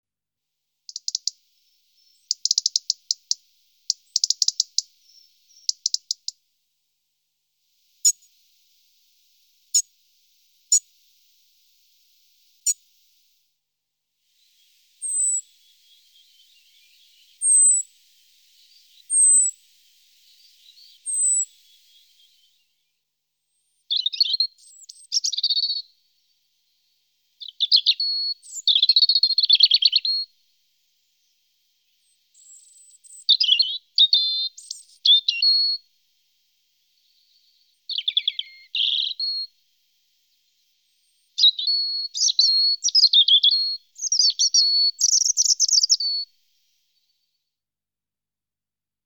Голоса птахів
U Вільшанка
erithacus_rubecula.MP3